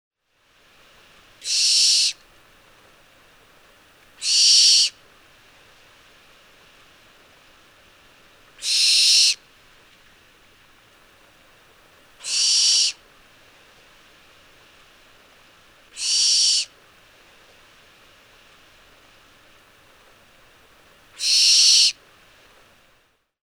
На этой странице собраны звуки сипухи — уникальные резкие крики, которые чаще всего издает самец во время полета или для привлечения самки.
Звук крика обыкновенной сипухи